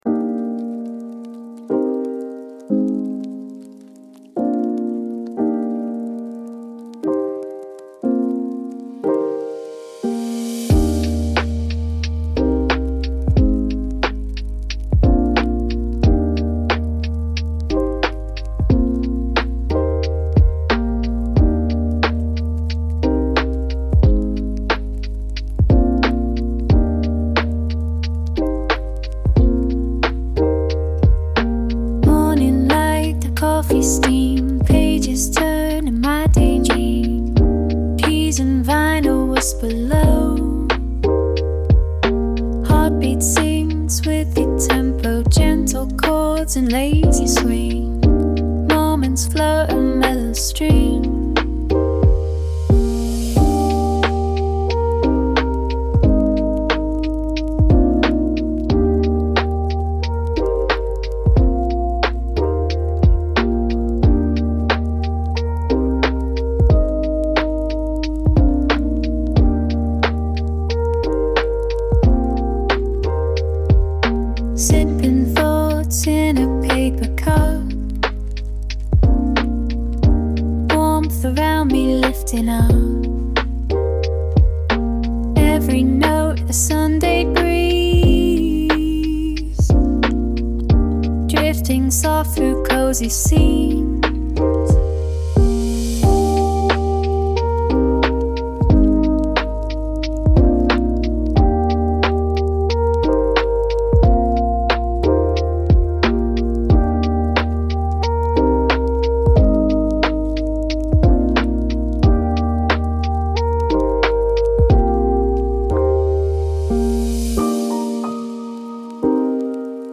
cozy beats with jazzy vibes.